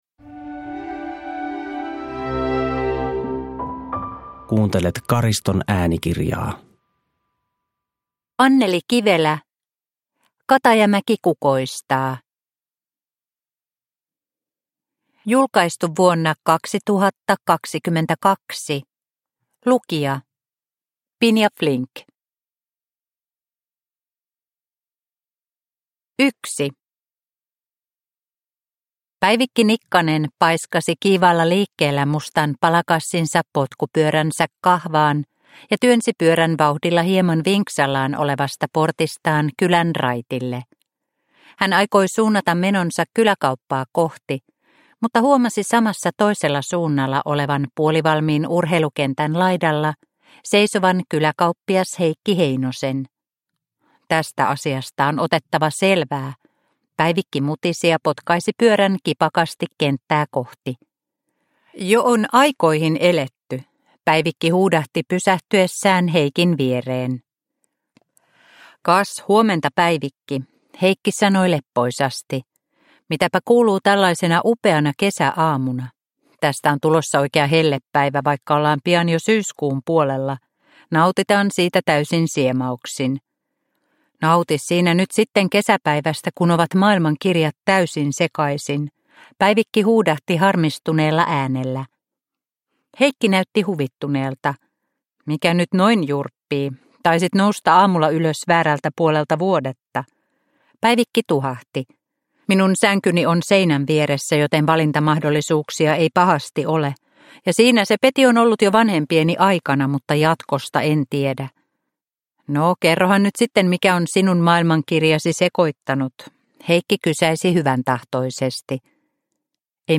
Katajamäki kukoistaa (ljudbok) av Anneli Kivelä